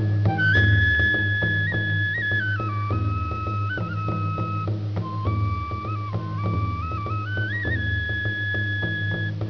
taiko.wav